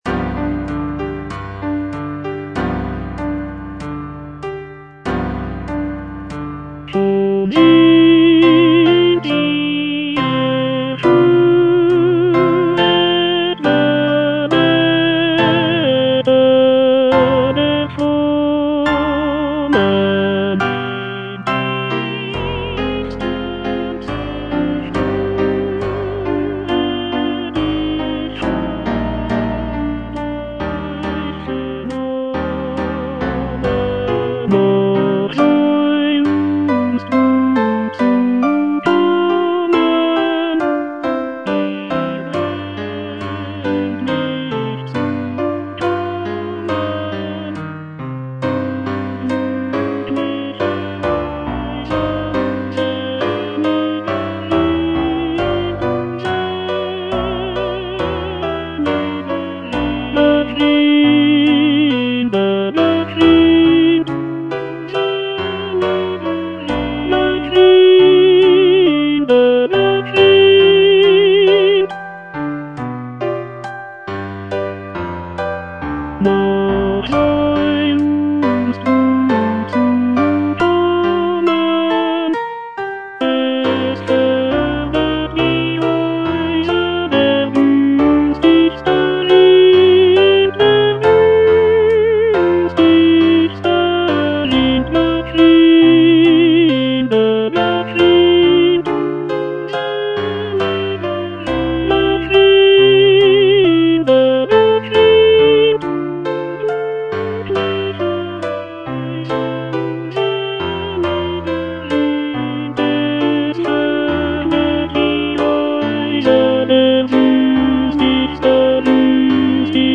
J. BRAHMS - RINALDO OP.50 Schon sind sie erhöret (tenor I) (Voice with metronome) Ads stop: auto-stop
It is an oratorio for soloists, choir, and orchestra, based on the epic poem "Rinaldo" by Johann Wolfgang von Goethe.